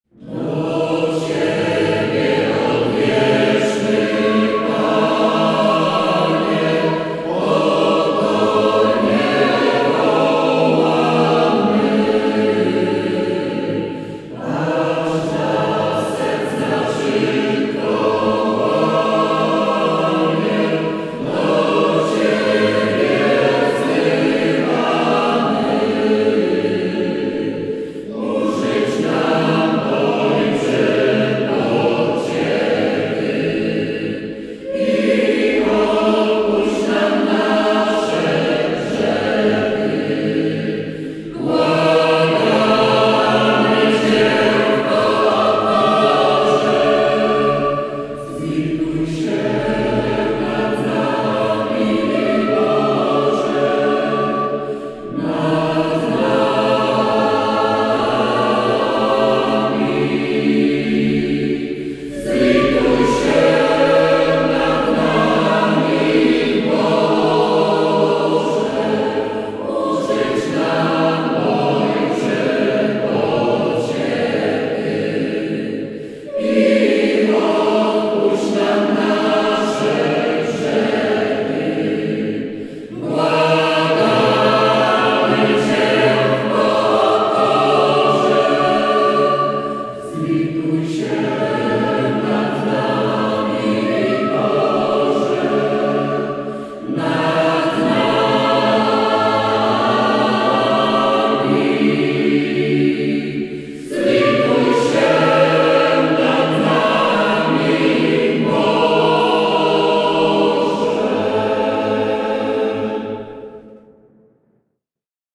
W trakcie Mszy św. chór zaśpiewał kilka pieśni:
Chórem
akompaniował na organach
Partie solowe żeńskie wykonywały